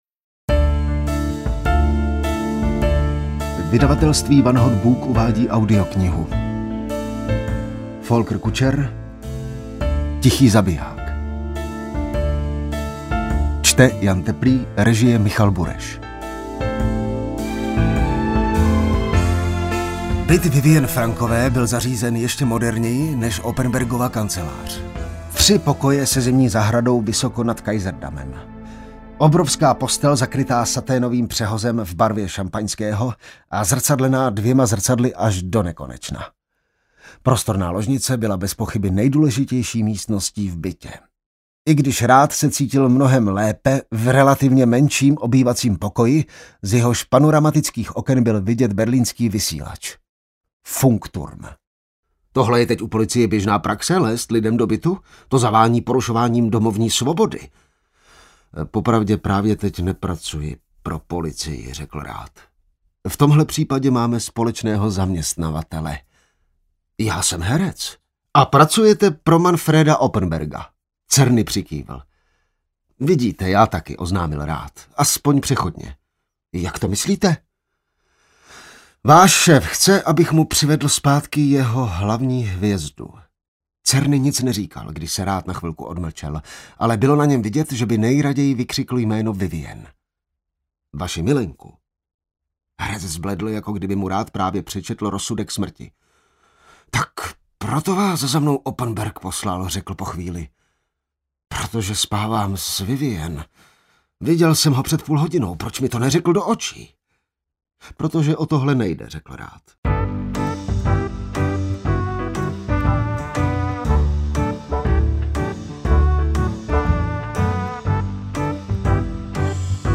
Tichý zabiják audiokniha